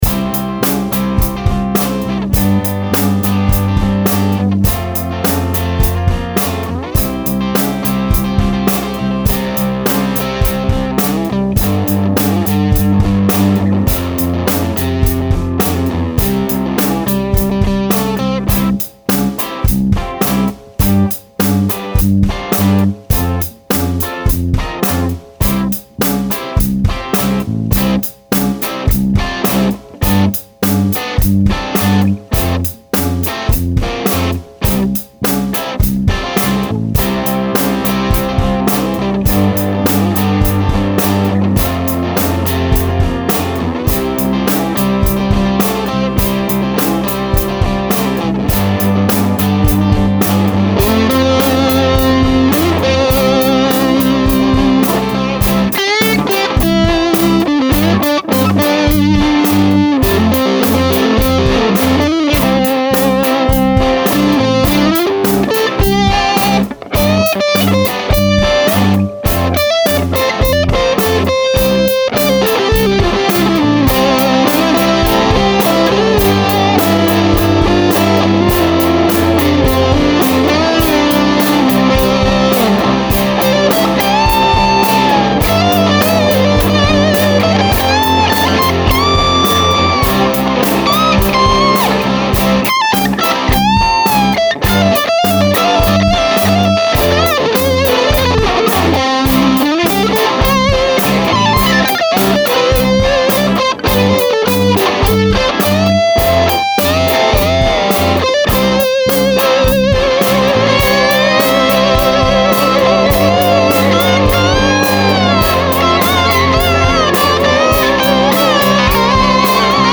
I laid the drums down (4 mics) and just copied and pasted a groove.
The bass I ran pretty low to keep it clean. It has a mid range-y punch on the bass and the boost was disengaged, volume at about noon.
All guitar parts were recorded with a Shure SM58 slightly off centre angled at the centre back of the speaker. TriFly into a 1-12 (Celestion V30) >SM58>Line6 UX8 (sound card) direct. I added a bit of reverb and compression on the drums and compressed the overall mix.
There are two distinct rhythm guitars and you hear them panned left/right.
There is a thickening guitar just off centre that had the boost engaged, but both levels around 9 or 10 o'clock…
The lead guitar was both channels around 3 o'clock and you hear me switch pickups (neck to bridge) part way through. The slide guitar is bad but I am learning how to be more confident with it…
Trifly_Demo.mp3